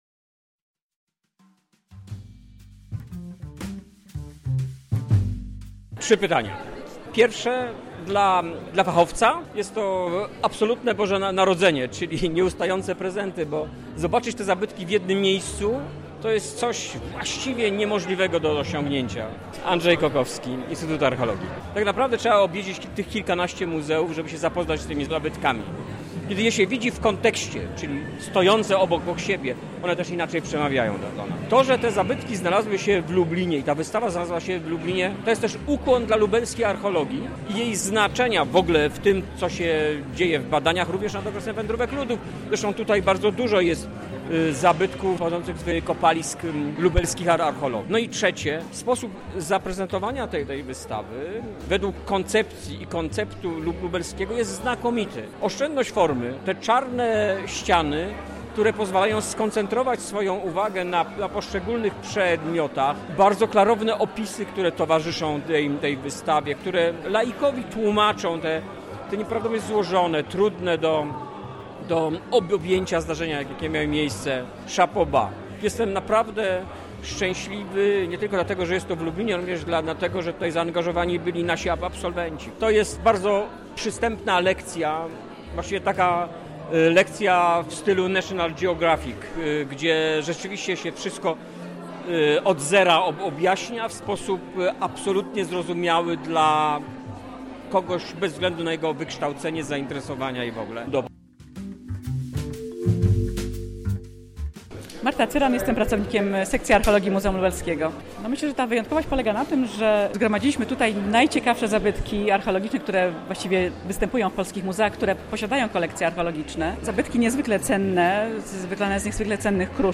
Dziś miało miejsce otwarcie wystawy.